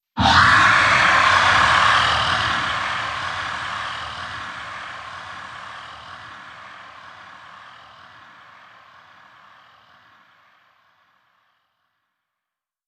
dragon-roar.d87c2b22.wav